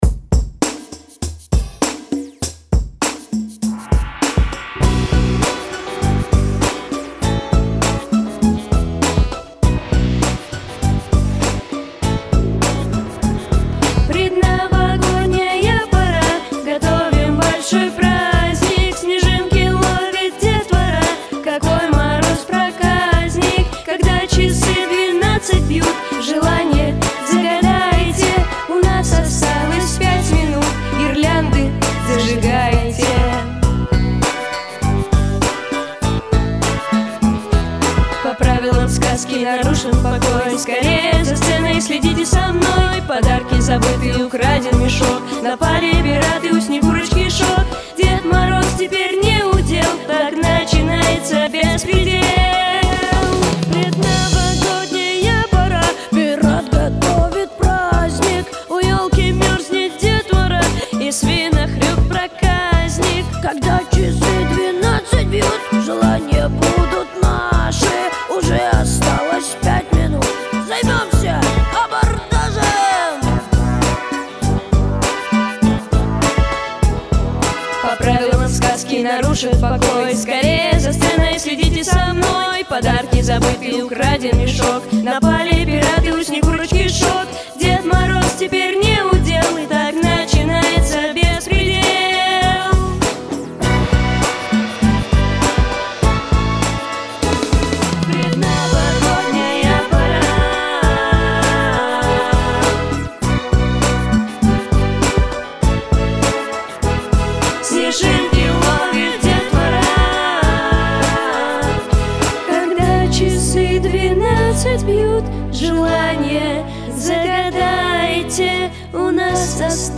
Записано в студии Easy Rider в октябре–декабре 2023 года